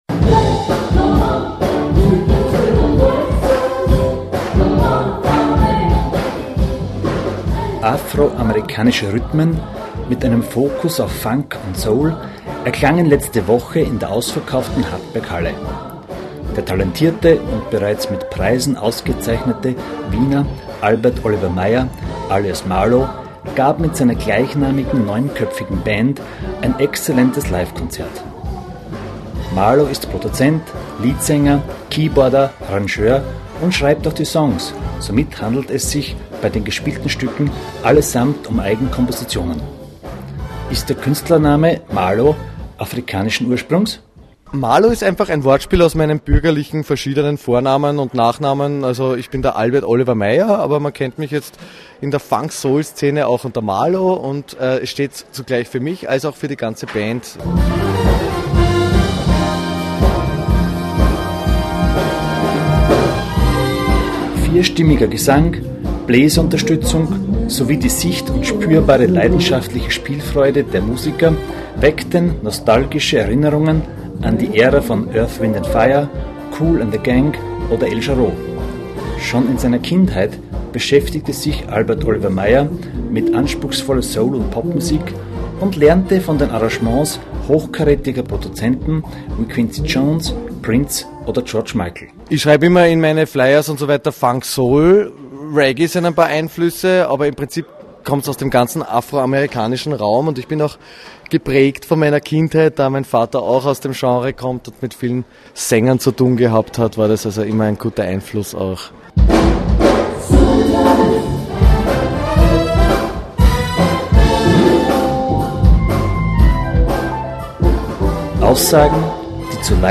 Radiobeitrag